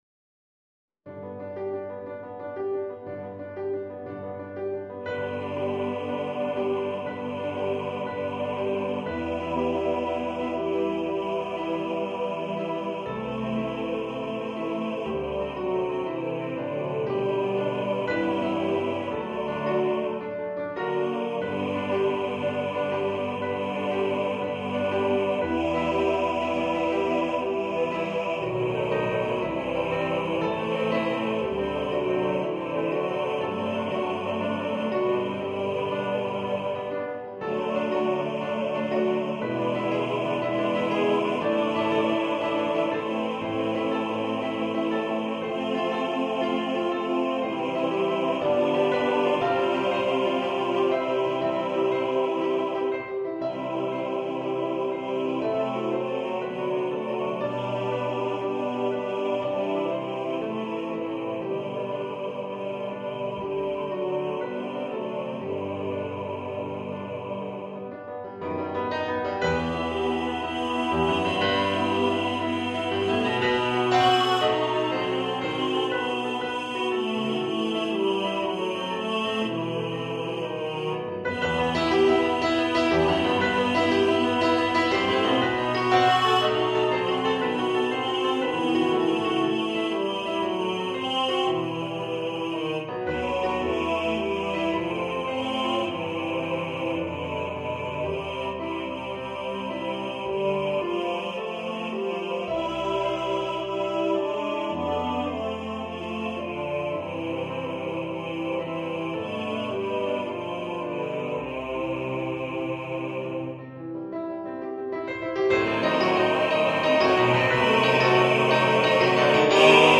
for TTBB
A carol for Christmas
for choir with orchestra or piano
Choir (SATB or SA+Men or SSA or TTBB)
(Choir - Male voices)